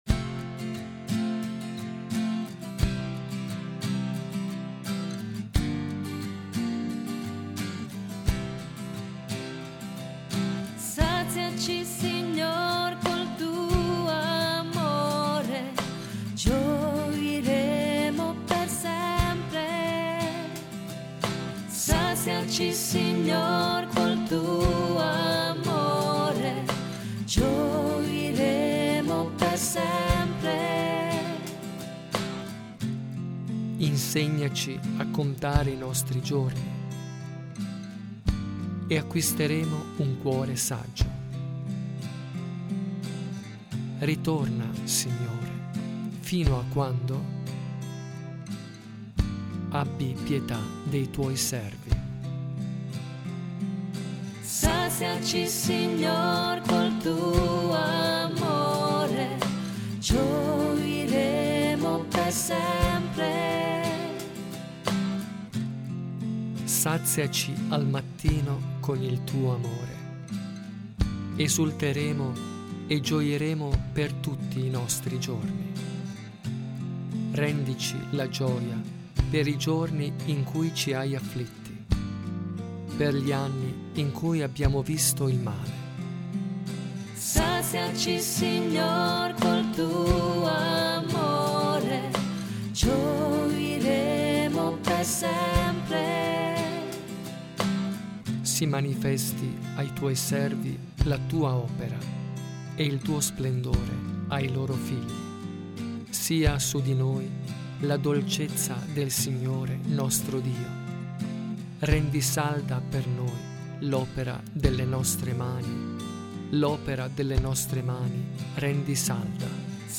SALMO RESPONSORIALE
RITORNELLO CANTATO